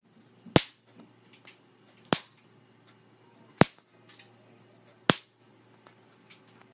描述：仿效肉被打耳光的歌